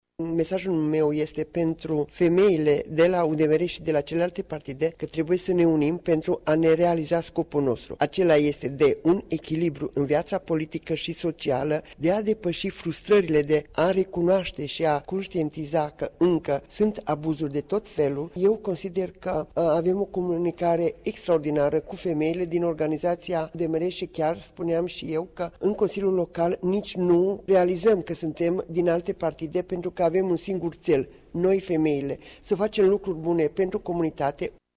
Aceasta a început la ora 10,00 în Sala mică a Palatului Culturii din municipiu în prezența mai multor personalități.